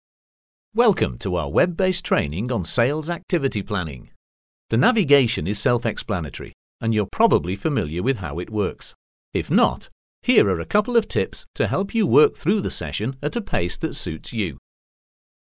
Sprecher für britisches / neutrales Englisch bei Werbe-, Image- und Dokumentarfilmen, Telefonansagen und Multimediaprojekten
britisch
Sprechprobe: eLearning (Muttersprache):